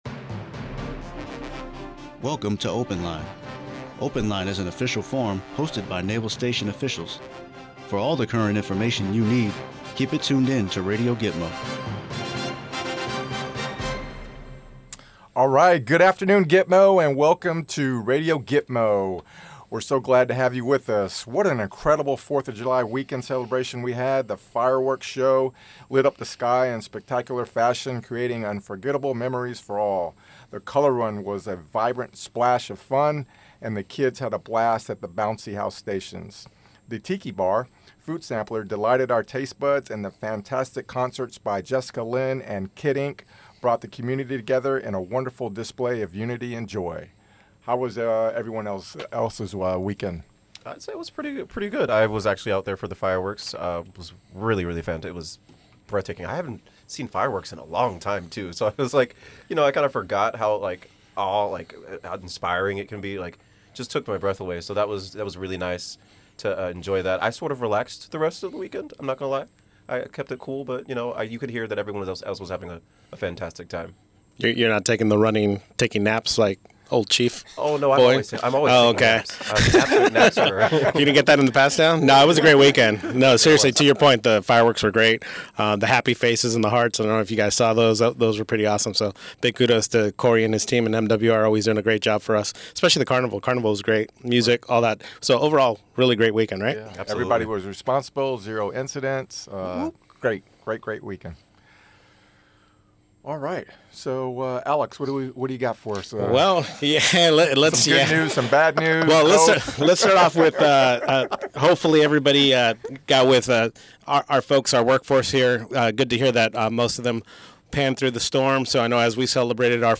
Open Line is an hour-long, weekly live broadcast featuring NAVSTA GTMO leadership.